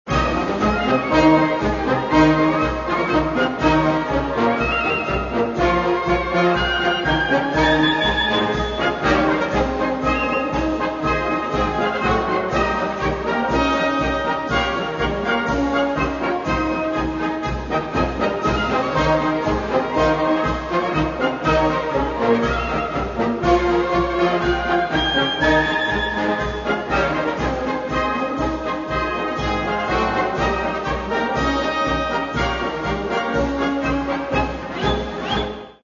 Каталог -> Народна -> Традиційне виконання